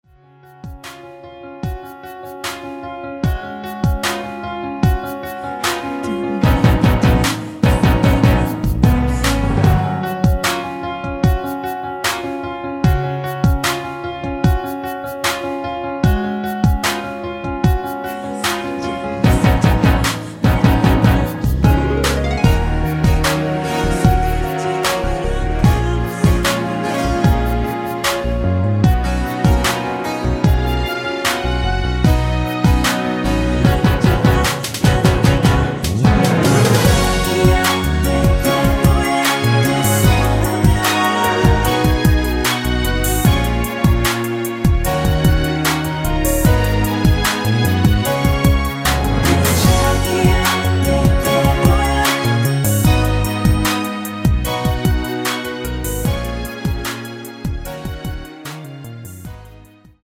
코러스 MR 입니다.
F#
◈ 곡명 옆 (-1)은 반음 내림, (+1)은 반음 올림 입니다.
앞부분30초, 뒷부분30초씩 편집해서 올려 드리고 있습니다.
중간에 음이 끈어지고 다시 나오는 이유는